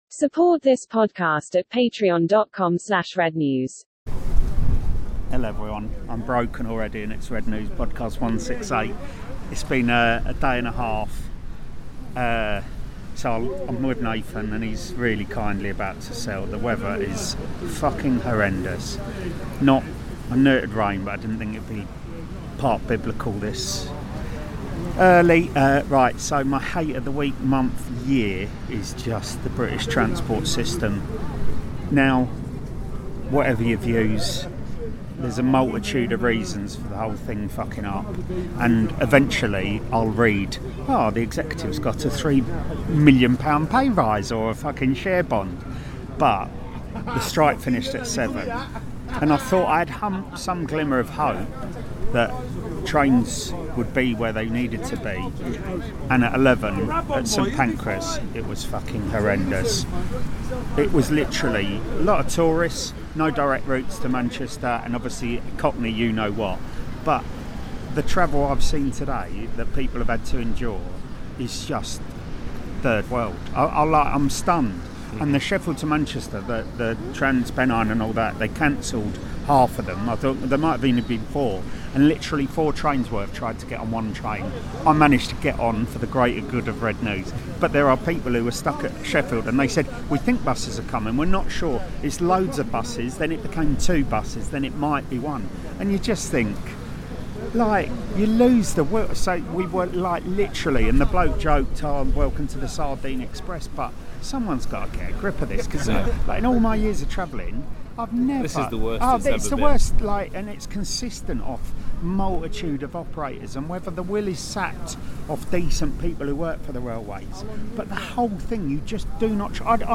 United beat Forest. Before the win and after it live from a seller's wedding as we say goodbye to 2022.